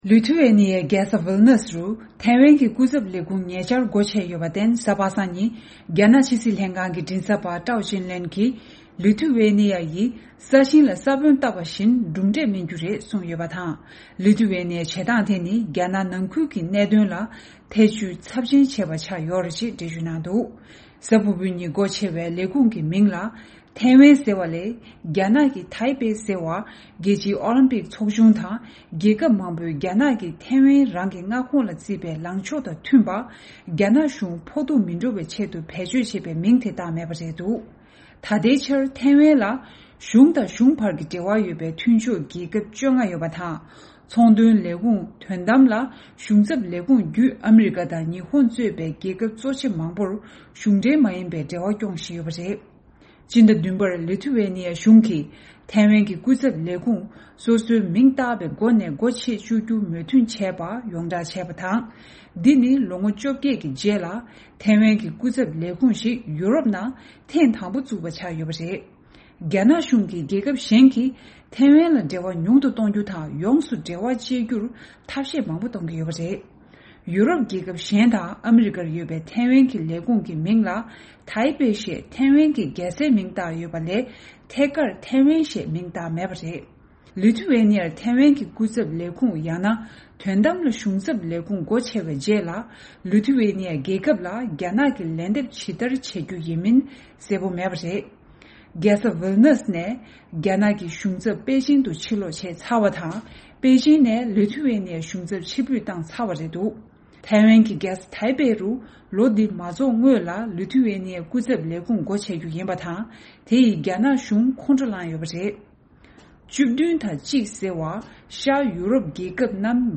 ཕབ་བསྒྱུར་དང་སྙན་སྒྲོན་ཞུས་གནང་གི་རེད།